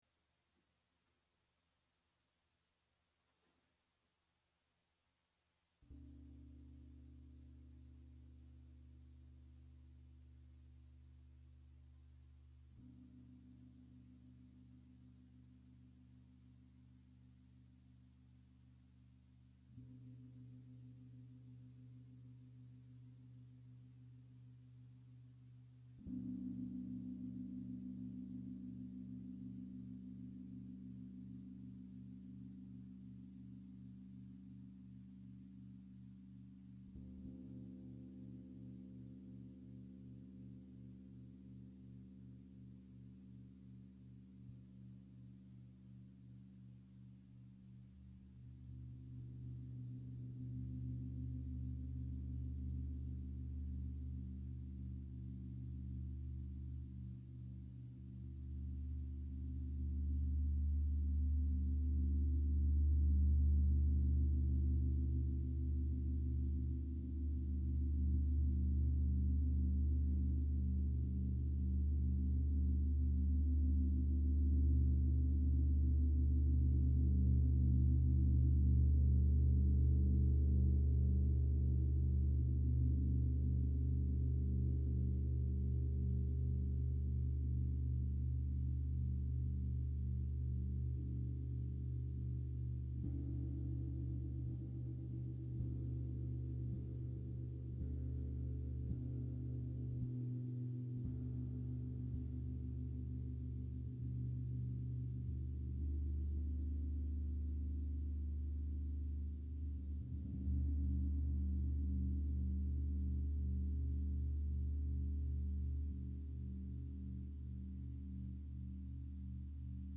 For TAM and live electronics.